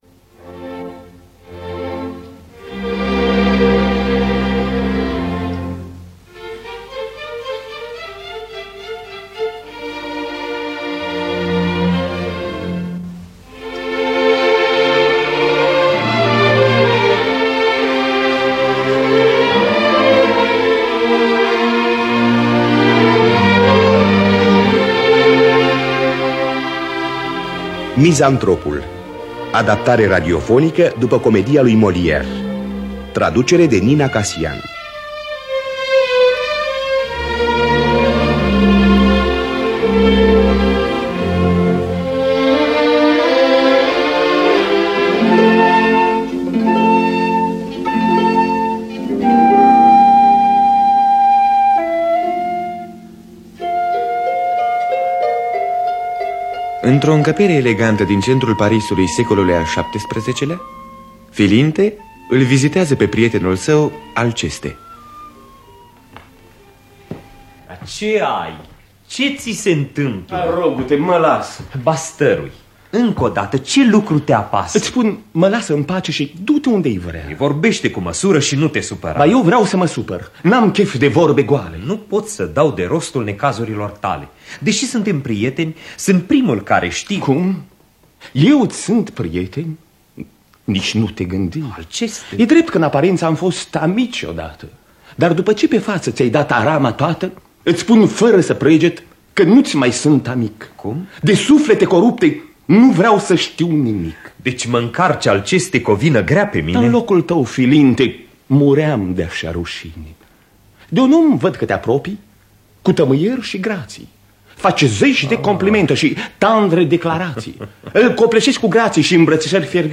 Mizantropul de Jean-Baptiste Poquelin de Molière – Teatru Radiofonic Online